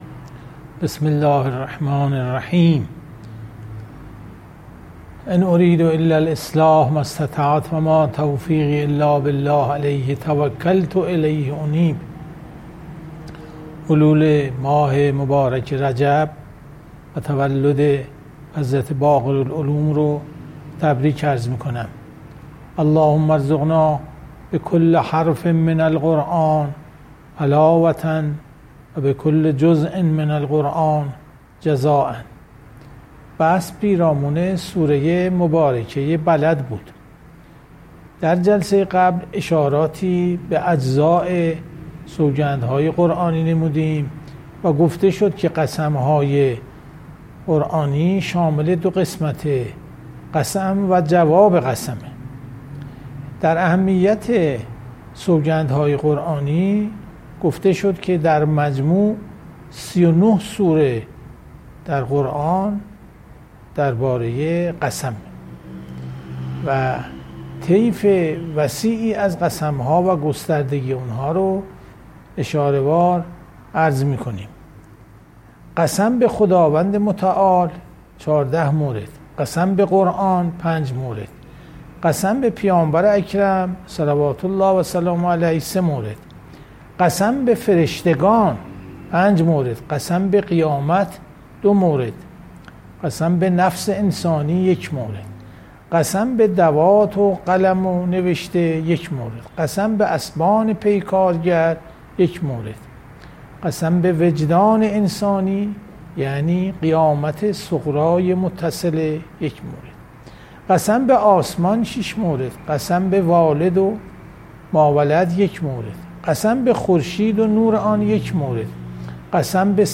• تفسیر قرآن